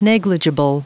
Prononciation du mot negligible en anglais (fichier audio)
Prononciation du mot : negligible